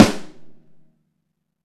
Roomy Snare Drum Sample A Key 71.wav
Royality free acoustic snare sound tuned to the A note. Loudest frequency: 974Hz
roomy-snare-drum-sample-a-key-71-ggw.mp3